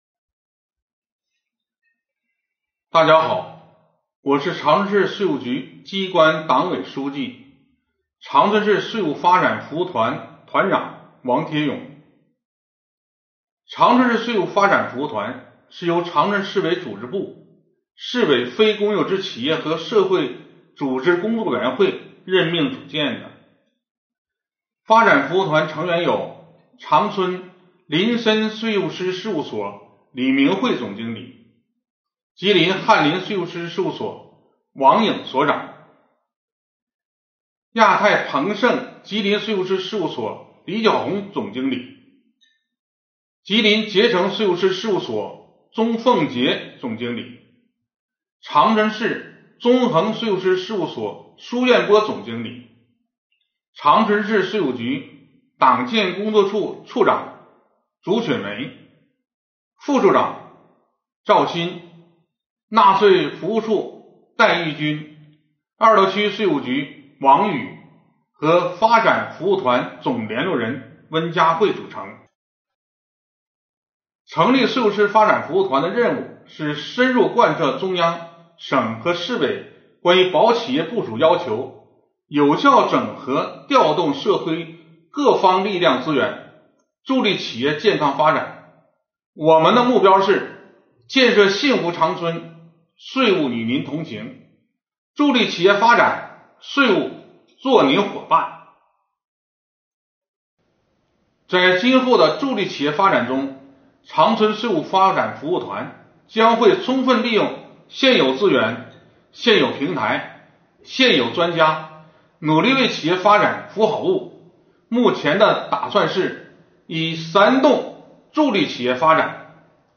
2020年第38期直播回放：长春市税务发展服务团系列之一：以“三动”助推企业发展